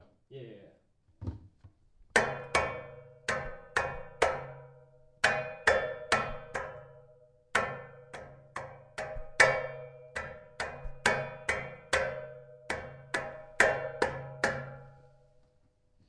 在一个回声的空间里，金属零件的碰撞声响起
描述：非常回声的空间：打开工具箱，卡扣扣，金属管，接头，工具的咔嗒声，距离和近距离都是如此。
标签： 回声 金属 叮当 工业
声道立体声